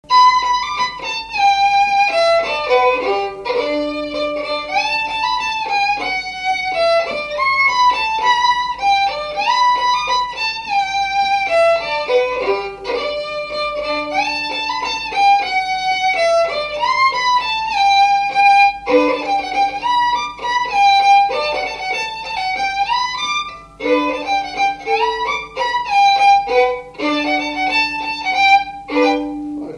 Mouchamps
instrumental
Pièce musicale inédite